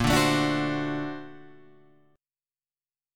Bb+M9 chord